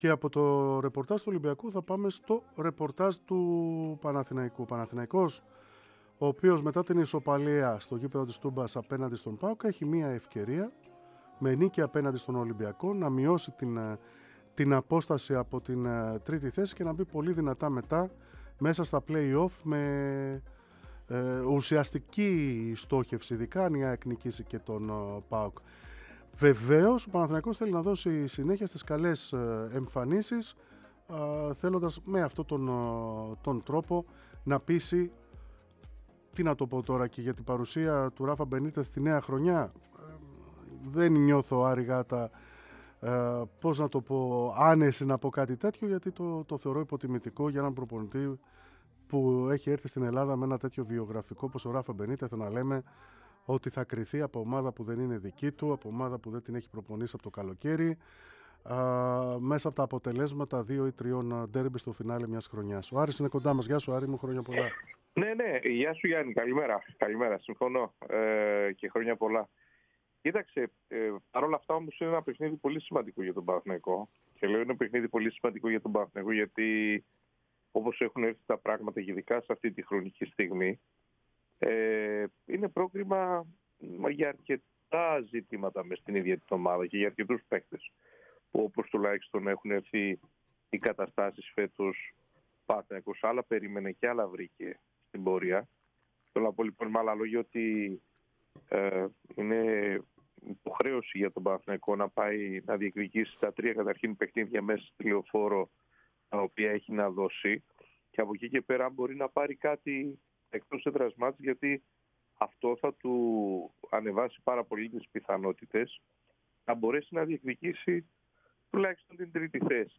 Τα τελευταία νέα του Παναθηναϊκού εν όψει του προσεχούς ντέρμπι με τον Ολυμπιακό στο γήπεδο της Λεωφόρου Αλεξάνδρας μετέφερε μιλώντας στον αέρα της ΕΡΑ ΣΠΟΡ και στην εκπομπή "3-5-2"